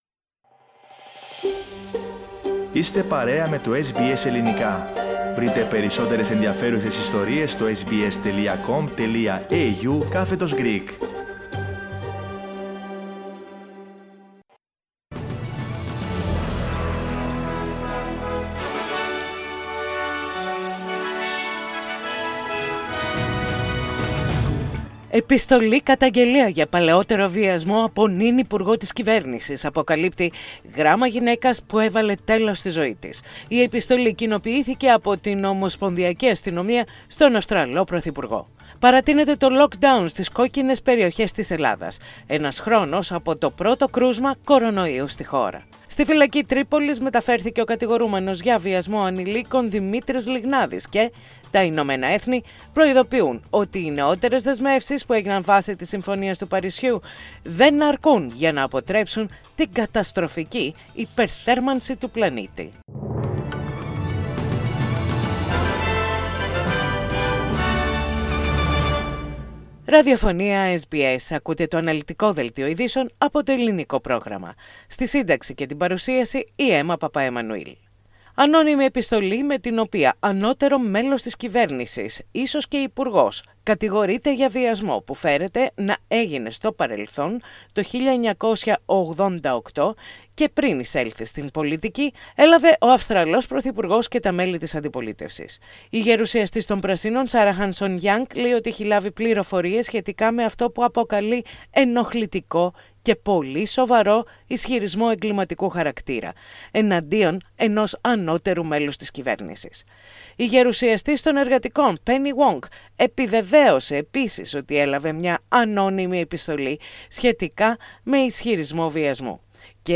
Ειδήσεις στα Ελληνικά - Σάββατο 27.2.21